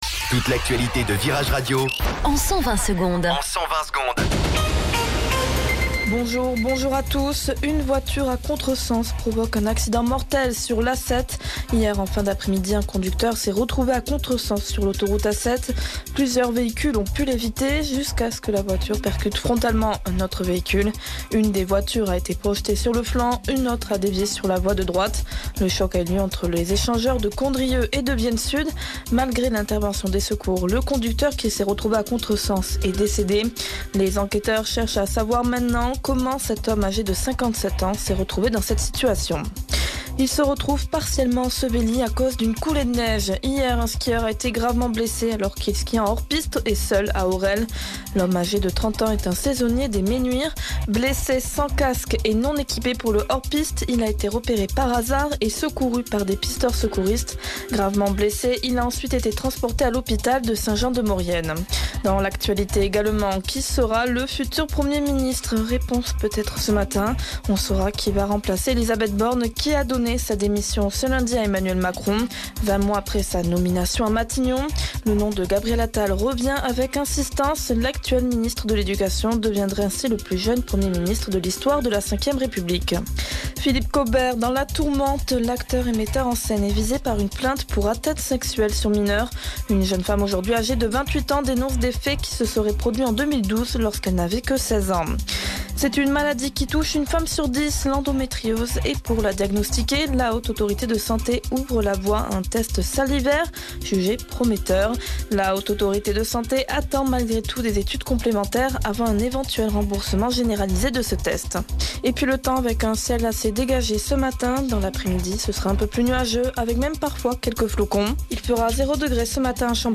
Flash Info Grenoble